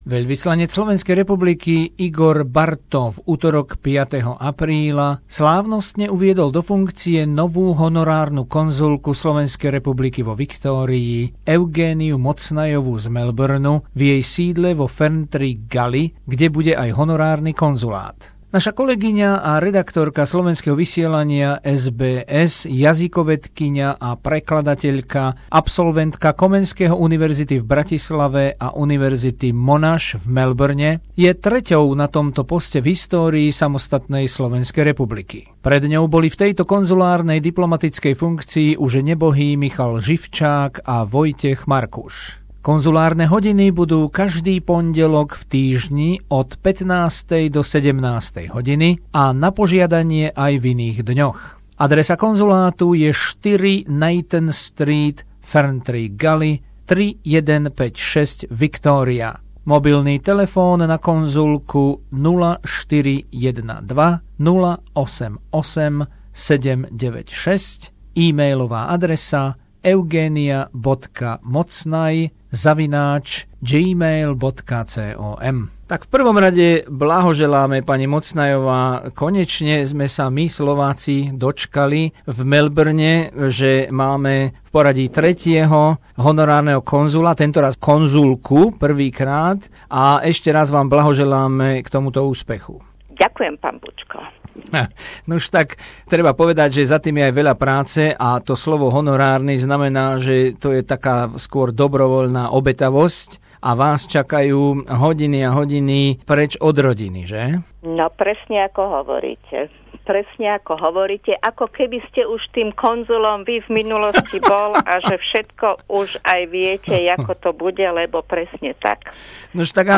Rozhovor s Eugéniou Mocnayovou - novou honorárnou konzulkou SR vo Viktórii